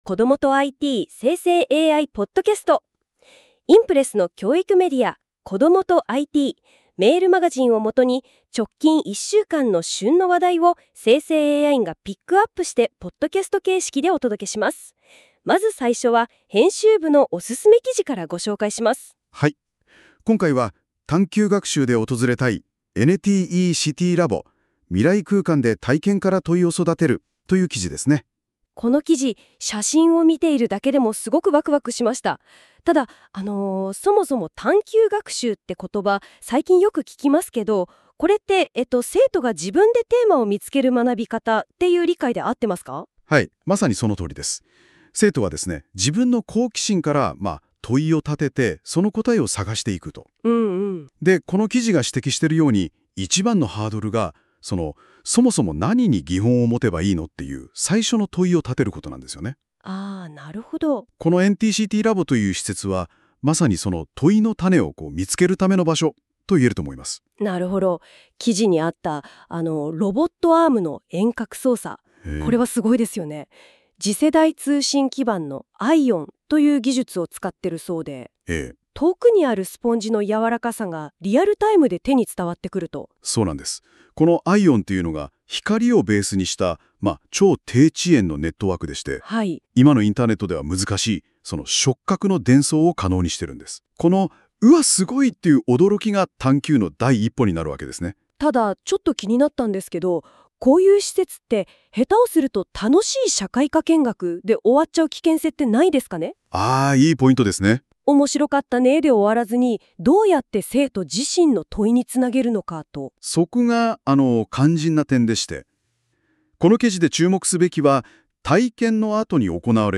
※生成AIによる読み上げは、不自然なイントネーションや読みの誤りが発生します。 ※この音声は生成AIによって記事内容をもとに作成されています。